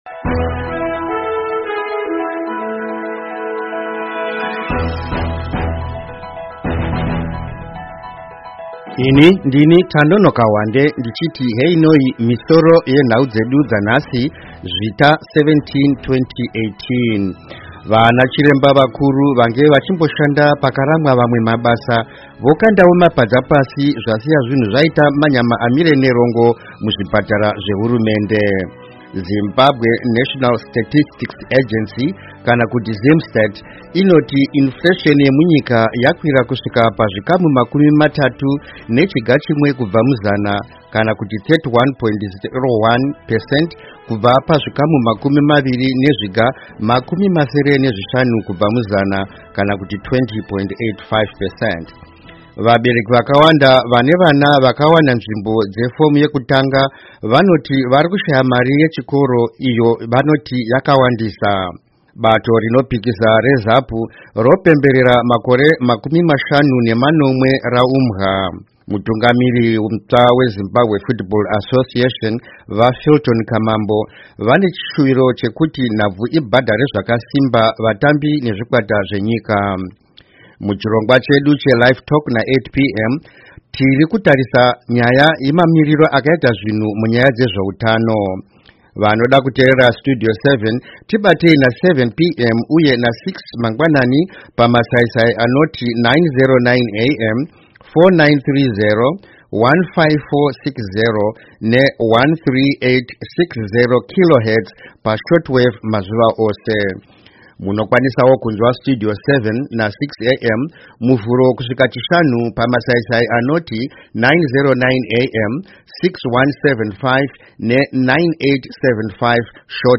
Misoro yenhau.